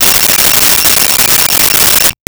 Cell Phone Ring 01
Cell Phone Ring 01.wav